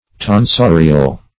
Tonsorial \Ton*so"ri*al\, a. [L. tonsorius, fr. tonsor a